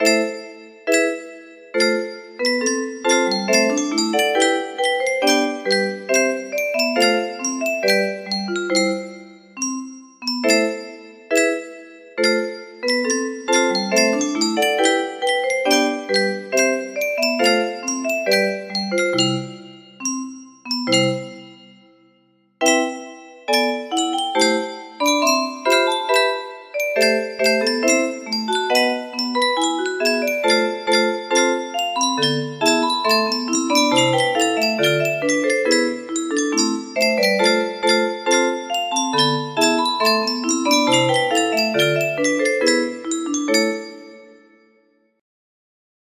Marche Pour La Ceremonie Des Turcs by Jean-Baptiste LULLY music box melody